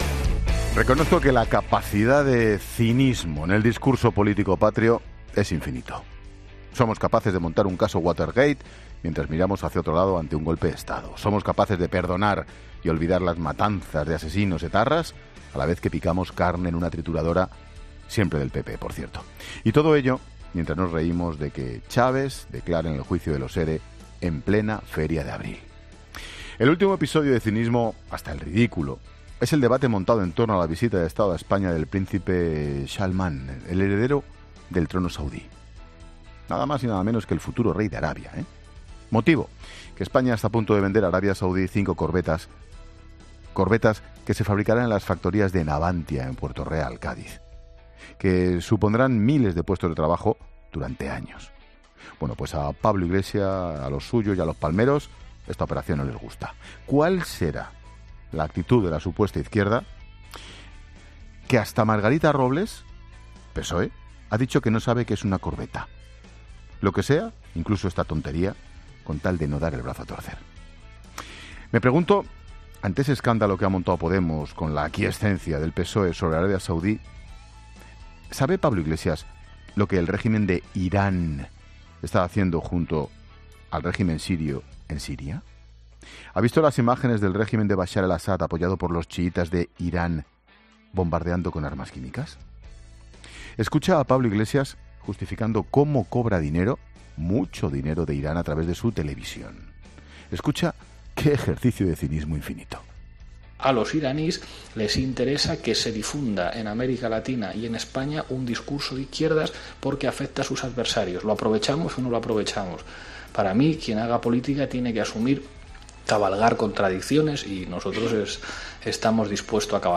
Monólogo de Expósito
El comentario de Ángel Expósito sobre las contradicciones de Pablo Iglesias a raíz de la visita del príncipe heredero saudí.